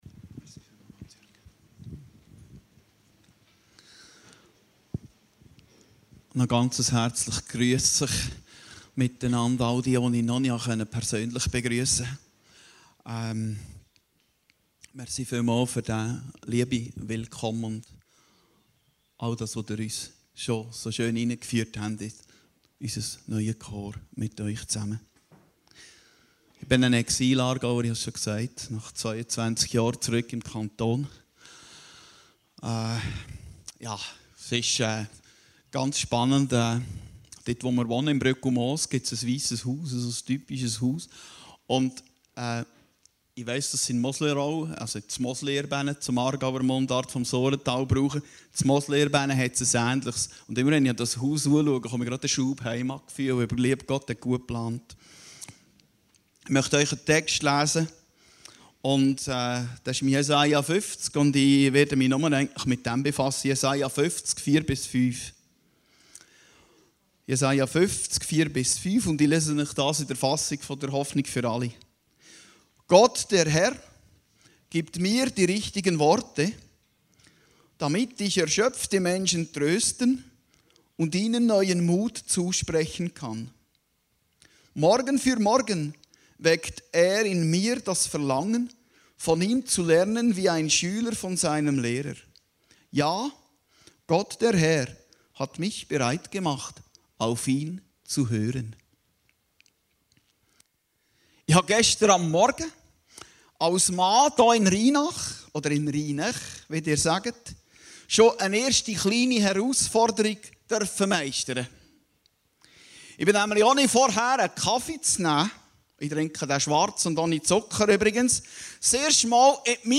Predigten Heilsarmee Aargau Süd